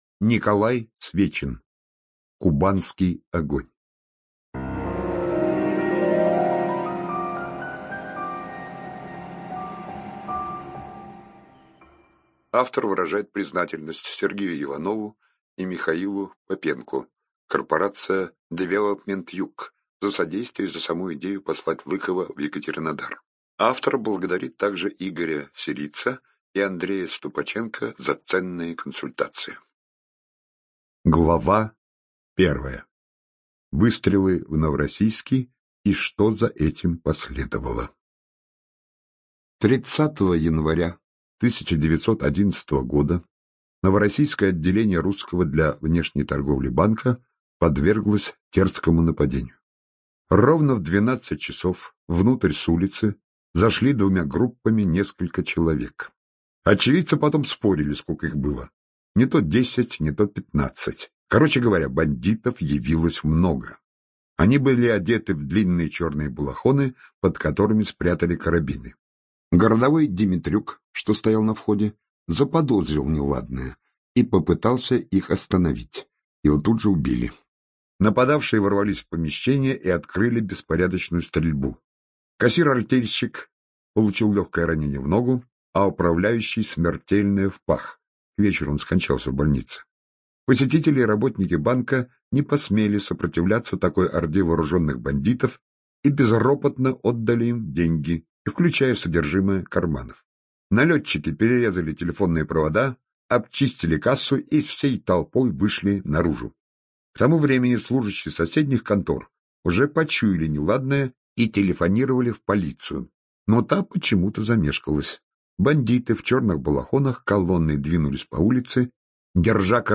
ЖанрДетективы и триллеры